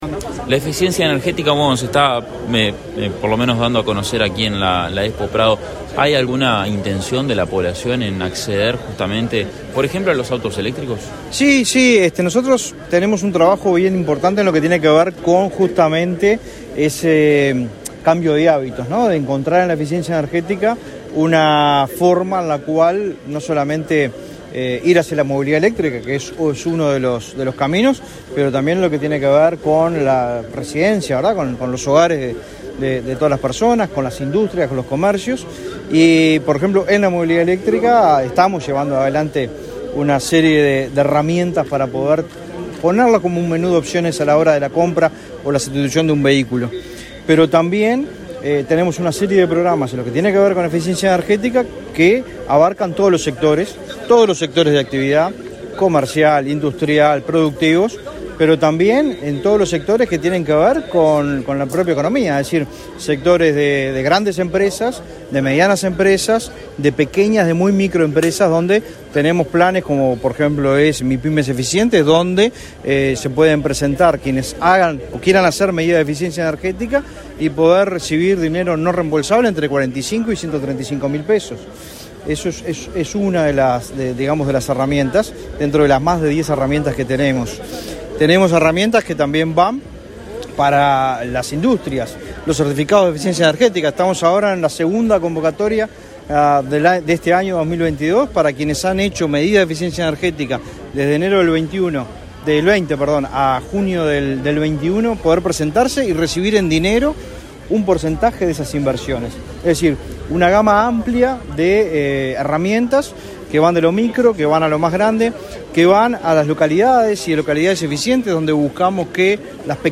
Declaraciones del director nacional de Energía
El director nacional de Energía, Fitzgerald Cantero, participó en la inauguración del stand de esa cartera en la Expo Prado.
Antes dialogó con la prensa.